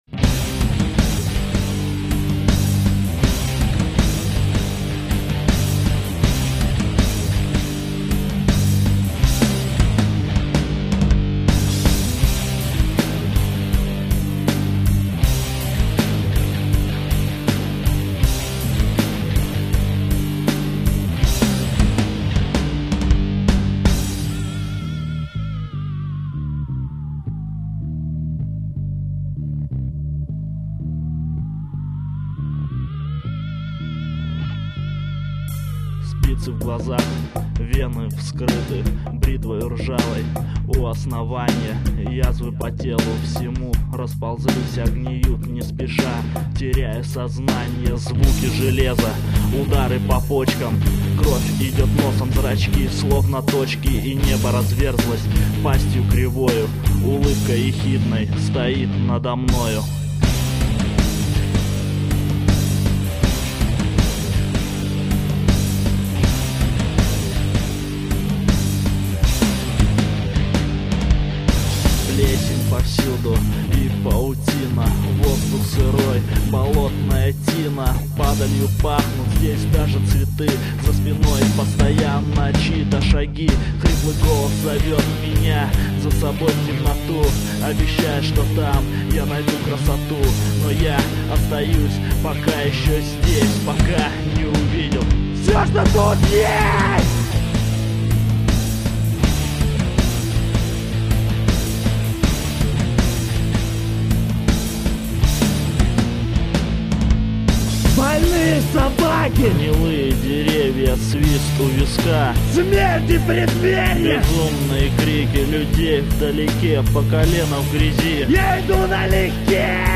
• Жанр: Альтернативная